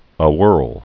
(ə-wûrl, -hwûrl)